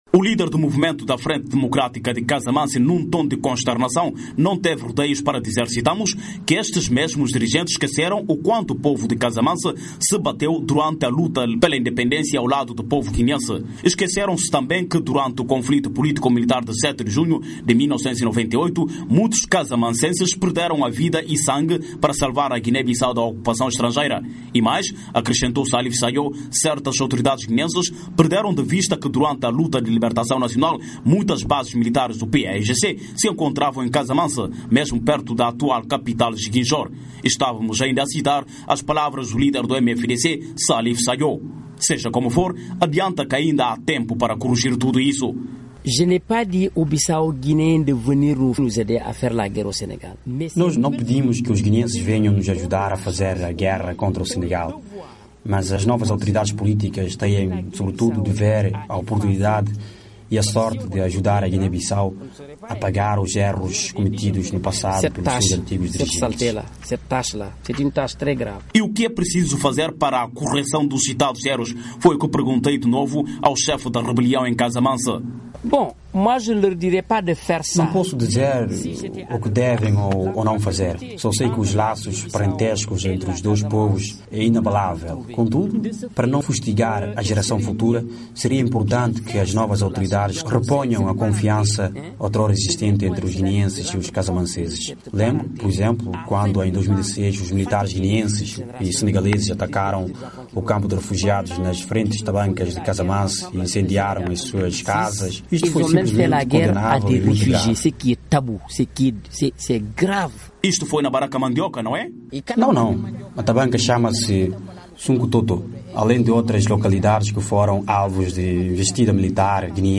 O líder do Movimento da Frente Democrática de Casamance, num tom de consternação, não teve rodeios para dizer, citamos: “que estes mesmos dirigentes esquecerem o quanto o povo de Casamance se bateu durante a luta pela independência ao lado do povo guineense. Esqueceram-se também que durante o conflito político militar de 7 de Junho de 1998, muitos casamanceses perderam a vida e sangue para salvar a Guiné-Bissau da ocupação estrangeira”.
Na entrevista à VOA, Sadio disse não pedir que “os guineenses venham-nos ajudar a fazer a guerra contra o Senegal, mas as novas autoridades políticas têm, sobretudo, o dever, a oportunidade e a sorte de ajudar a Guiné-Bissau a pagar os erros cometidos no passado pelos seus antigos dirigentes”.